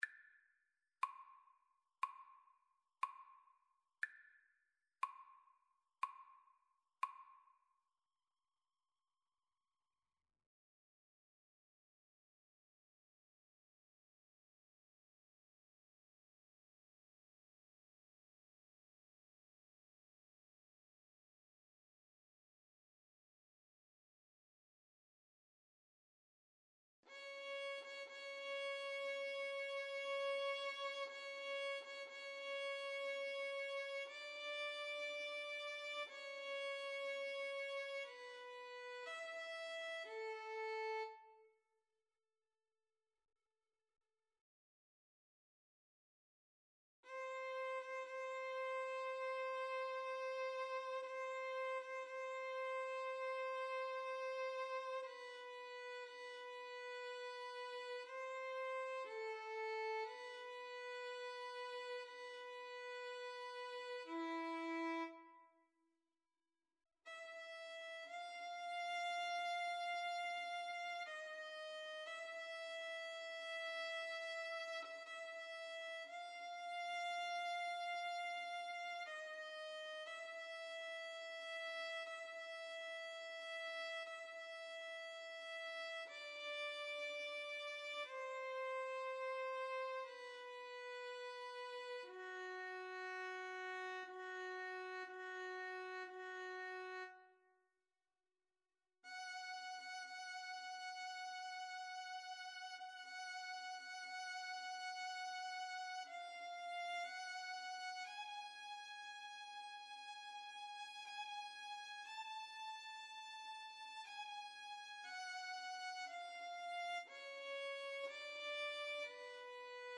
Violin 1Violin 2
2/2 (View more 2/2 Music)
Violin Duet  (View more Intermediate Violin Duet Music)
Classical (View more Classical Violin Duet Music)